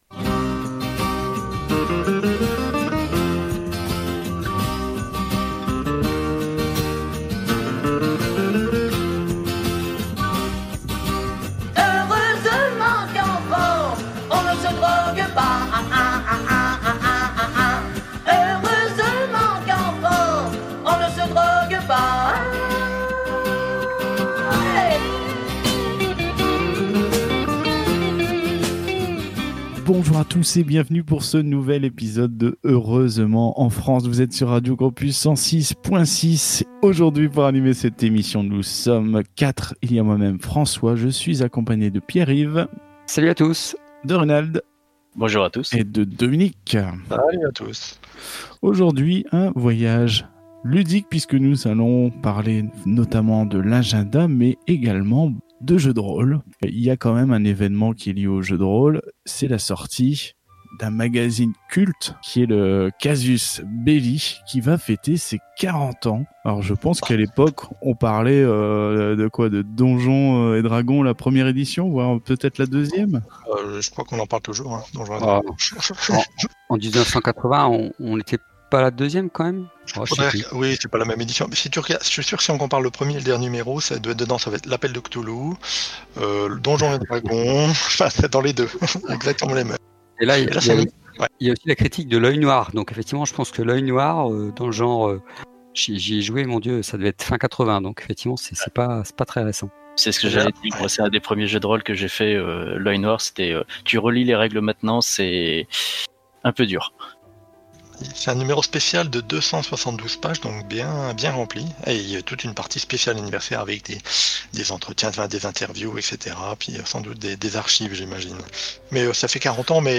Au sommaire de cet épisode diffusé le 8 novembre 2020 sur Radio Campus 106.6 :
– l’actualité du jeu de rôles – des chansons gégé